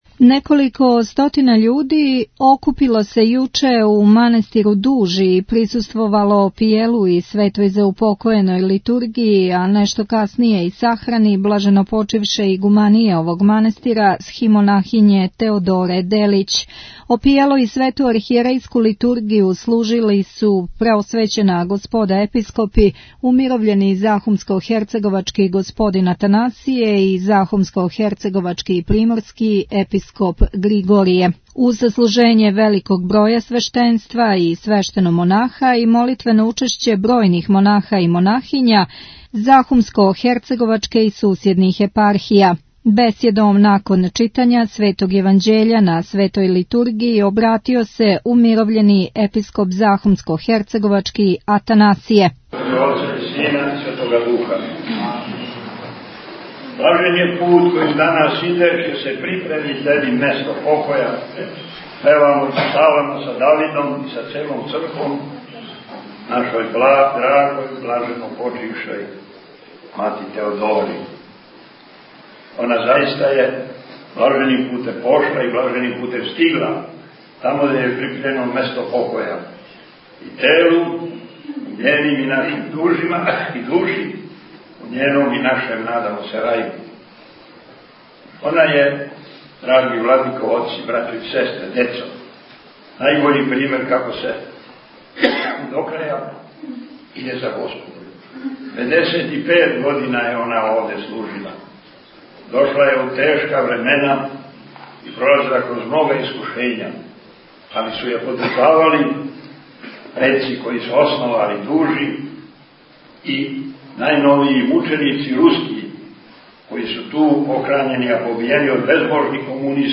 Опијело и Св. Архијерејску Литургију служила су обојица наших Владика, уз саслуживање великог броја свештеника и свештеномонаха и молитвено учешће бројних монаха и монахиња наше и сусједних епархија. Бесједом послије Св. Јеванђеља на Литургији се обратио Владика Атанасије, а ријечи последњег поздрава на крају сахране изрекао је Владика Григорије.